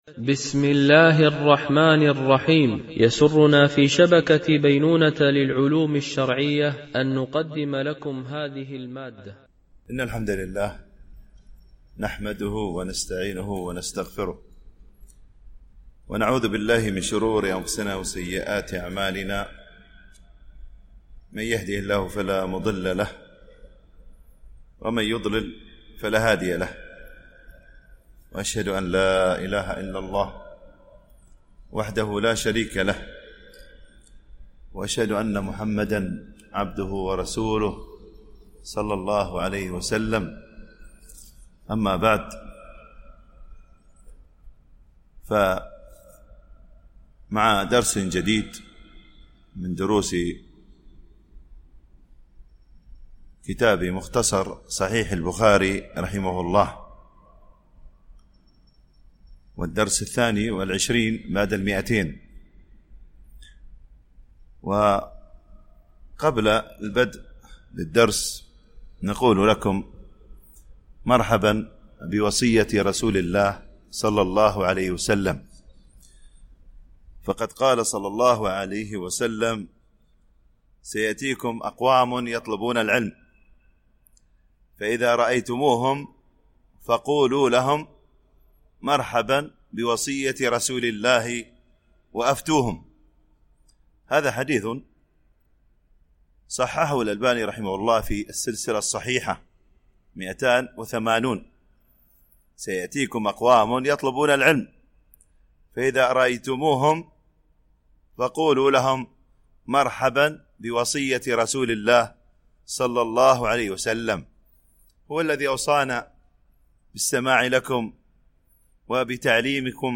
التنسيق: MP3 Mono 44kHz 64Kbps (CBR)